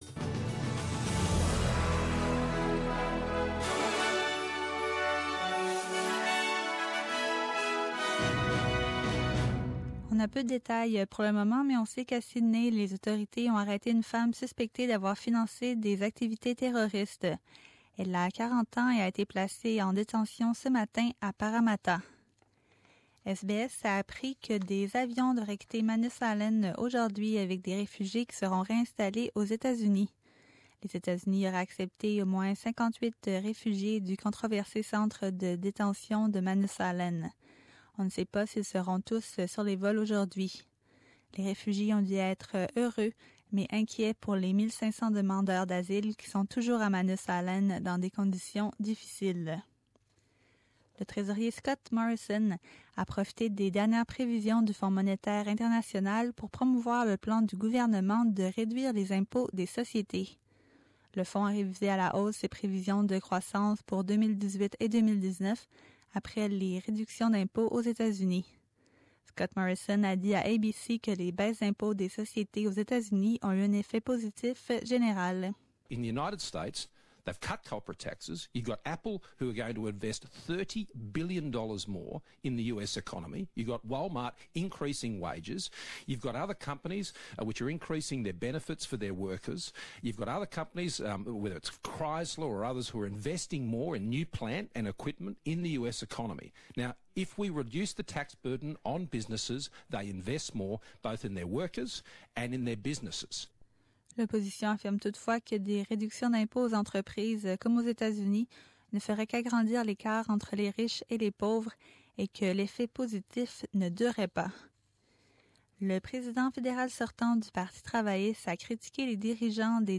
SBS French : Journal du 23 janvier 2018